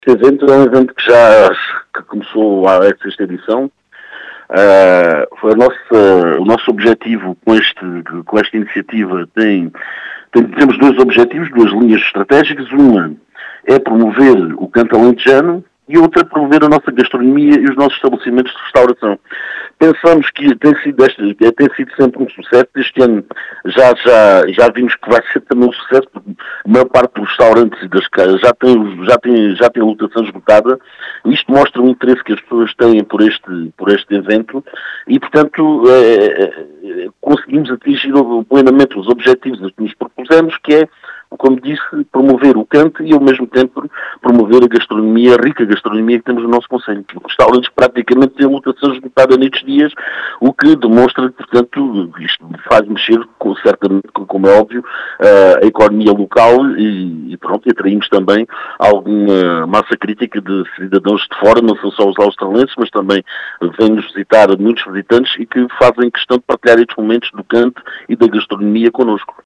As explicações são de Carlos Teles, presidente da Câmara de Aljustrel, que realçou a importância do evento para a economia local.